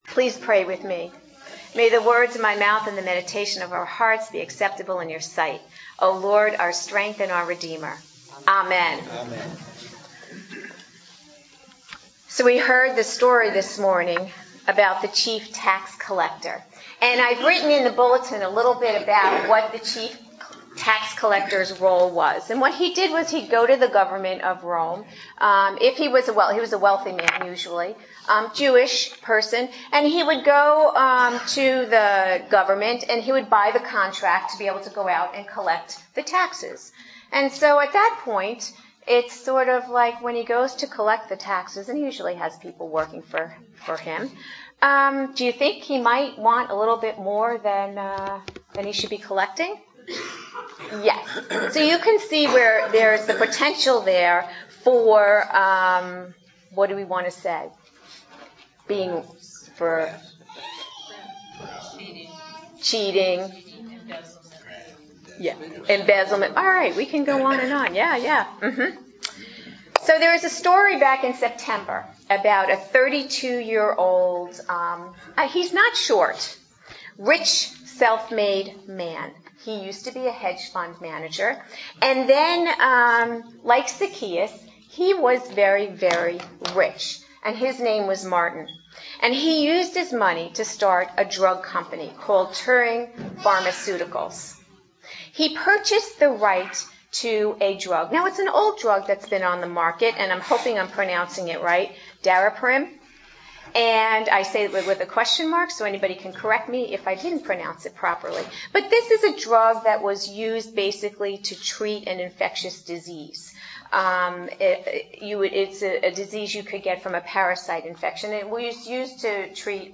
Series: Adult Sermons